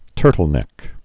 (tûrtl-nĕk)